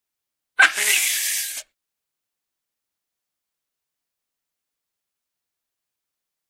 На этой странице собраны разнообразные звуки бабуина — от громких криков до ворчания и общения в стае.
Звук желтого павиана (бабуина)